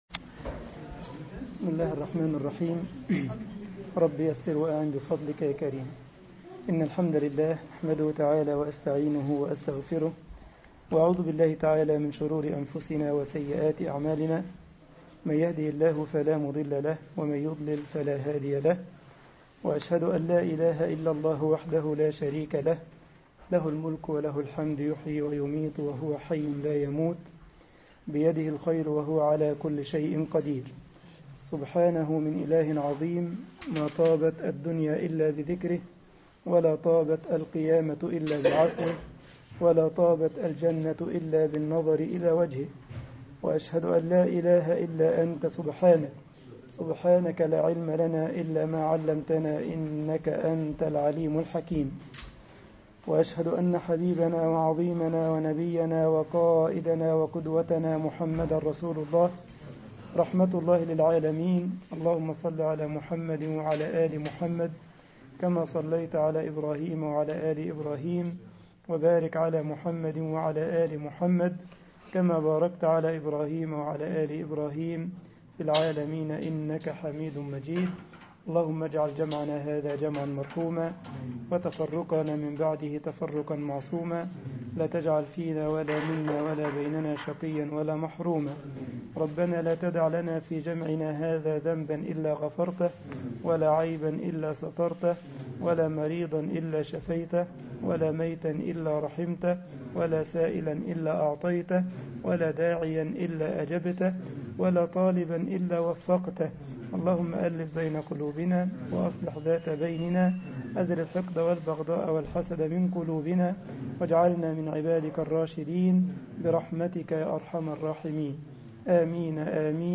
مسجد الجمعية الإسلامية بالسارلند ـ ألمانيا خطبة الجمعة